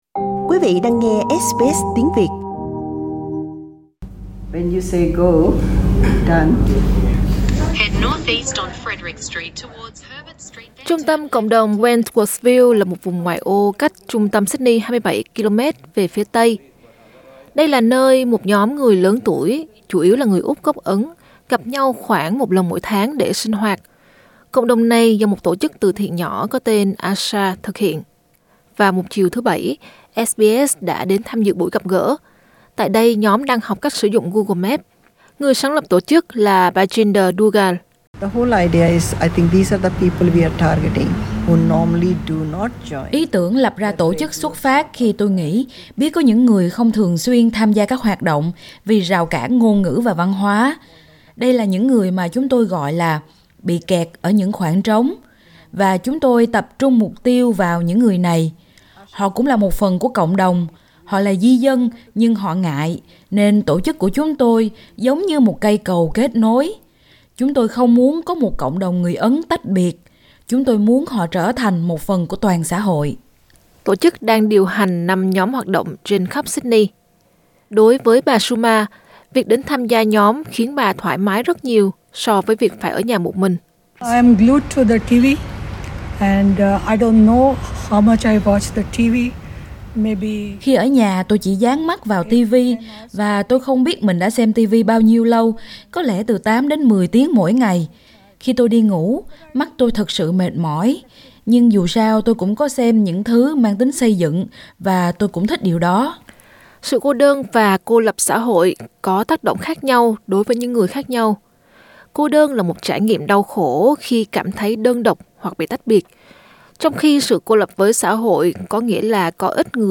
Đây là nơi một nhóm người lớn tuổi chủ yếu là người Úc gốc Ấn gặp nhau khoảng một lần mỗi tháng để sinh hoạt, hoạt động này do một tổ chức từ thiện nhỏ có tên AASHA thực hiện. Vào một chiều thứ Bảy, SBS đã đến tham dự buổi gặp gỡ, tại đây nhóm đang học cách sử dụng Google Maps.